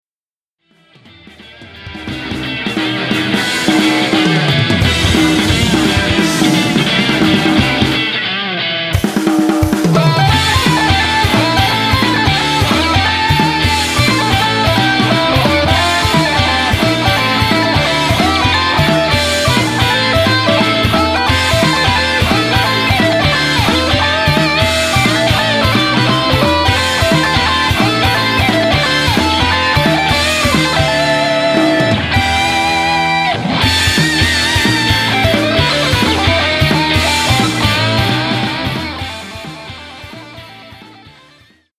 東方アレンジCD